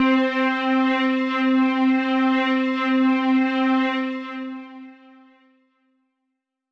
Lush Pad 2 C5.wav